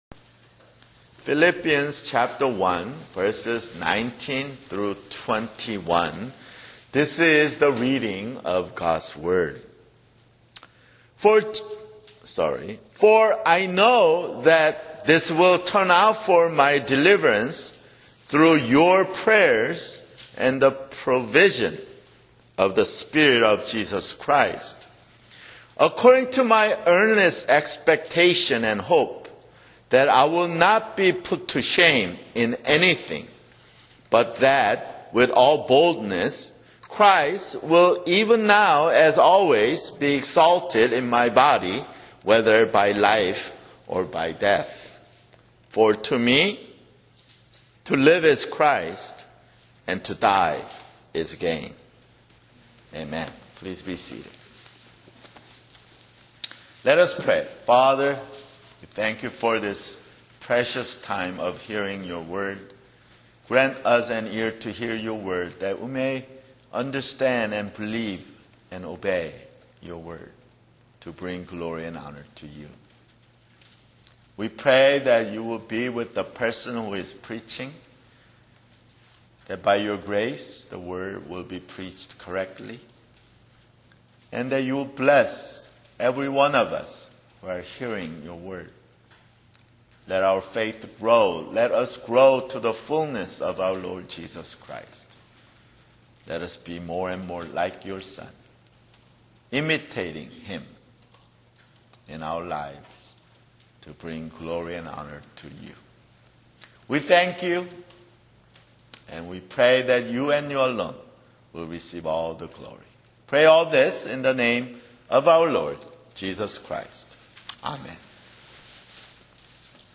[Sermon] Philippians (16)